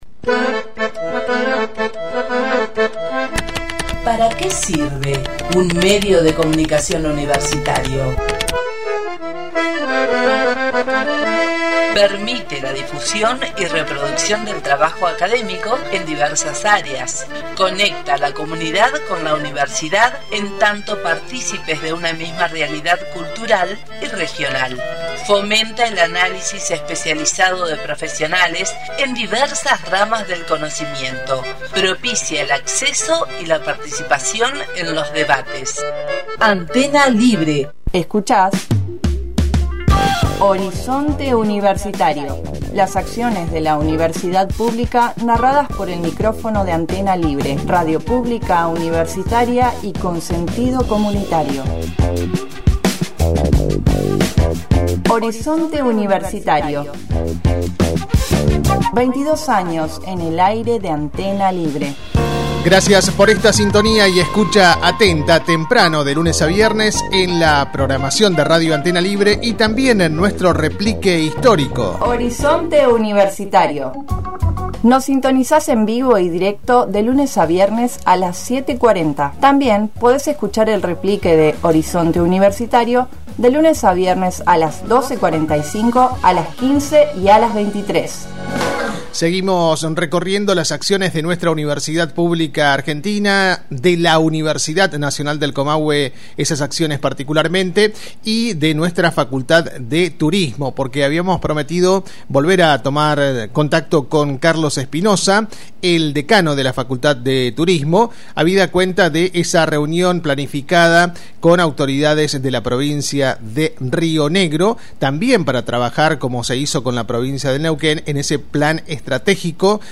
En diálogo con Horizonte Universitario